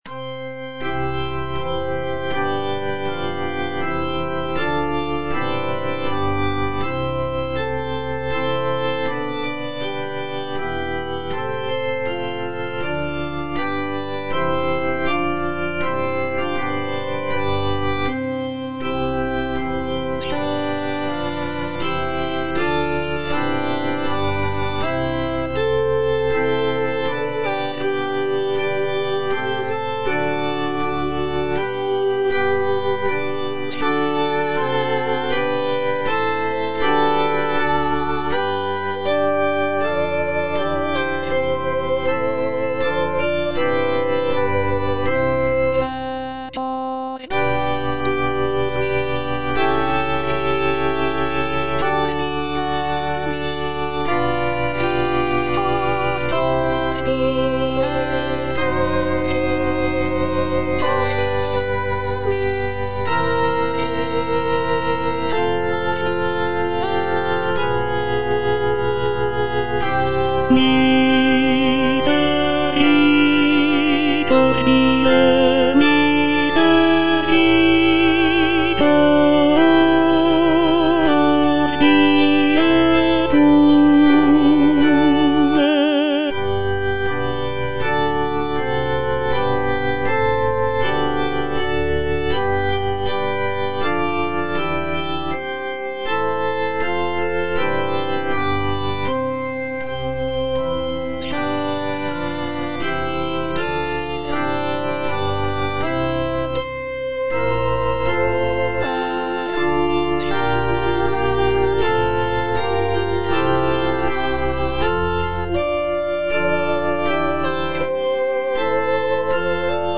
B. GALUPPI - MAGNIFICAT Suscepit Israel - Tenor (Voice with metronome) Ads stop: auto-stop
"Magnificat" by Baldassare Galuppi is a sacred choral work based on the biblical text of the Virgin Mary's song of praise from the Gospel of Luke.
The work features intricate vocal lines, rich harmonies, and dynamic contrasts, creating a powerful and moving musical experience for both performers and listeners.